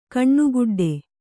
♪ kaṇṇuguḍḍe